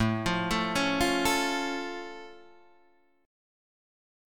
A+ chord {5 8 7 6 6 5} chord